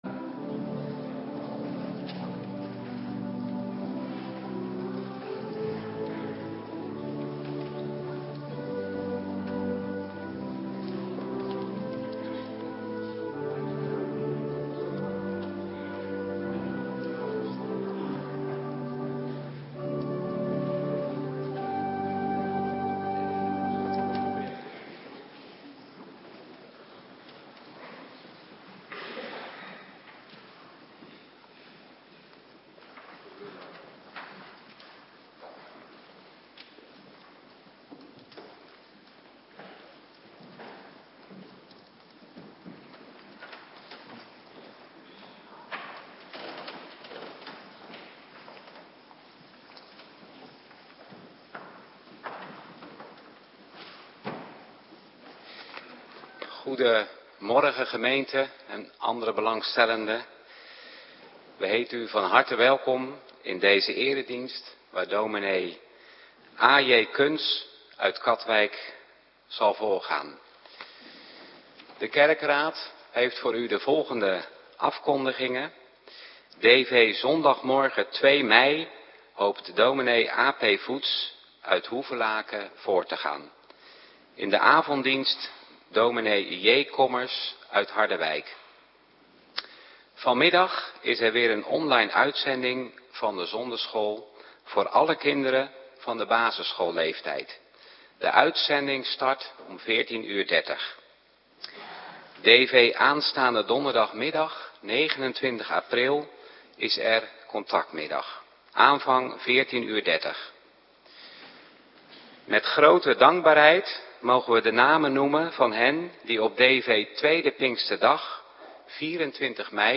Morgendienst - Cluster 3
Locatie: Hervormde Gemeente Waarder